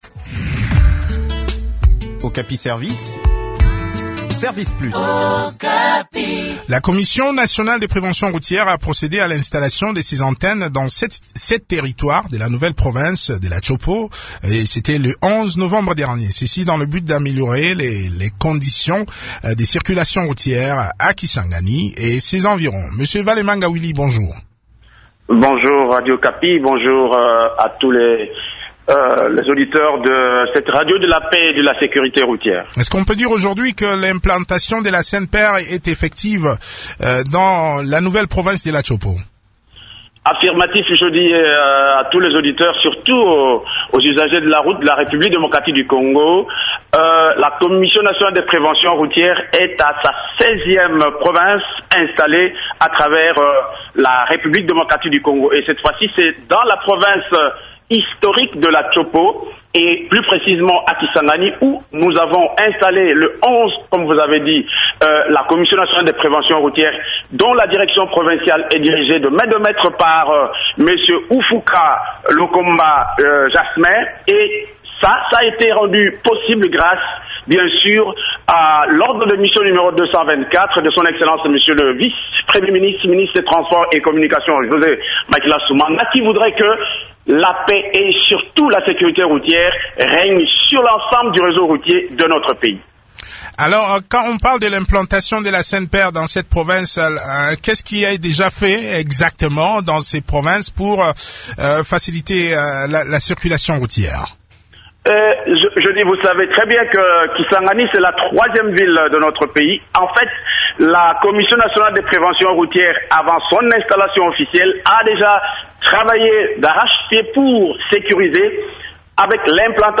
s’entretient sur ce projet avec Vale Manga, président de la CNPR.